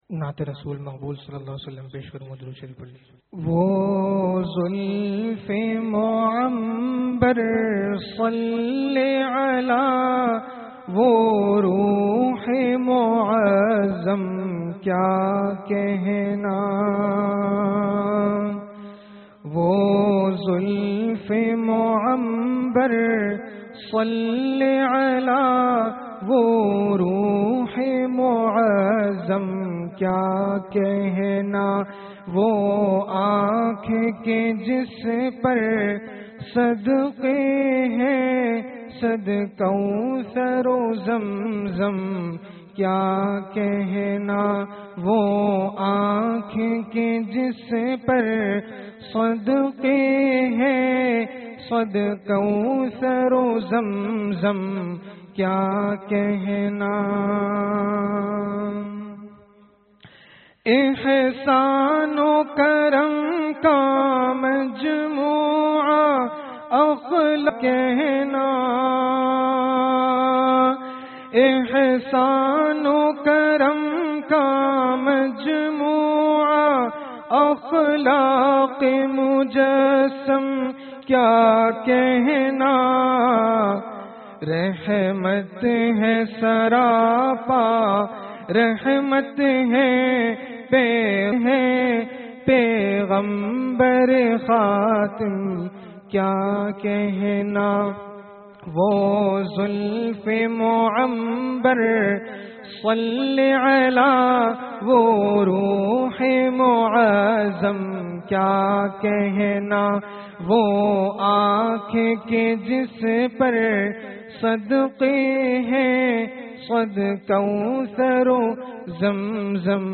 Bayanat
Musalmanon ke 03 tabqe (jummah byan)